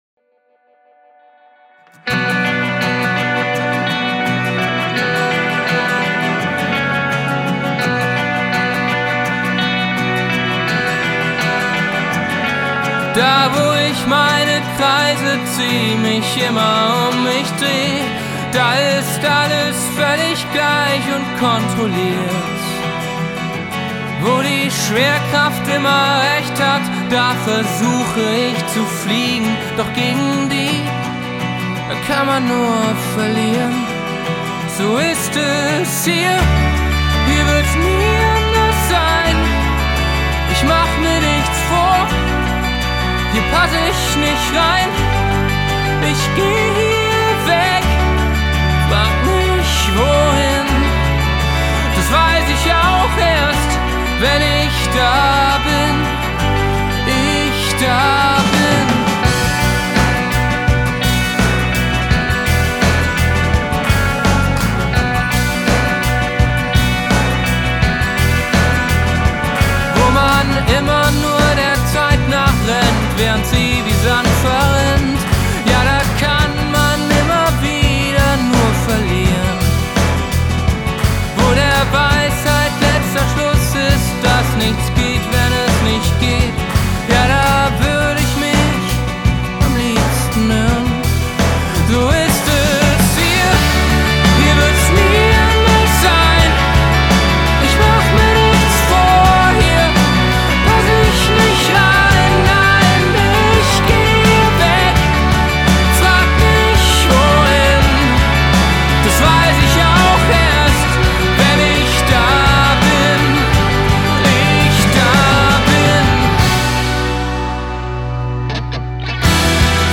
Rockband
eingängliche Melodien